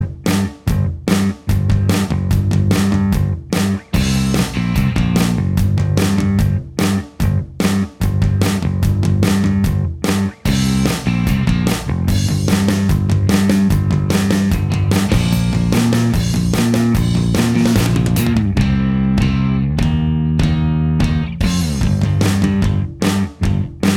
Minus Lead And Solo Guitar Punk 3:59 Buy £1.50